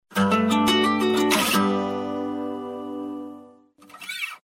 Index of /phonetones/unzipped/LG/KU5500/Message tones
Acoustic Guitar.mp3